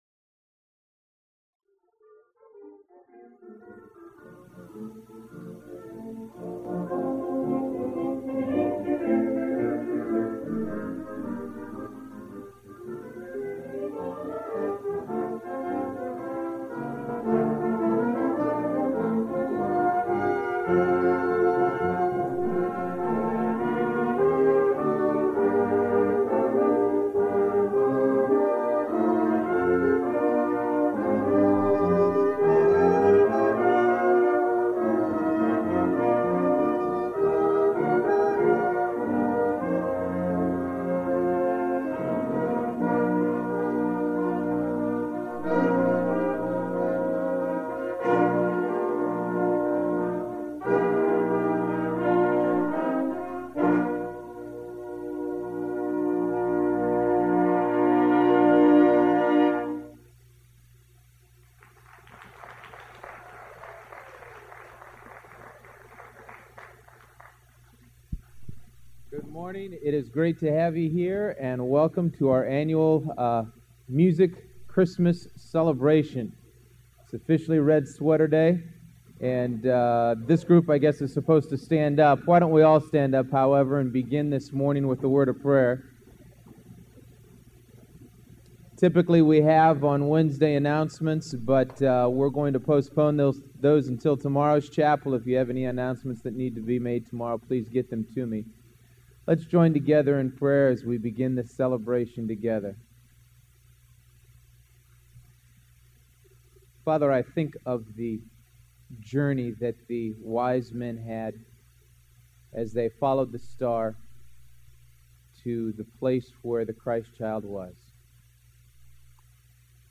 This is the annual Christmas Chapel from 09 Dec. 1998 recorded in Herrick Auditorium.
leads the congregation in the singing of Christmas hymns.